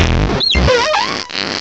cry_not_skuntank.aif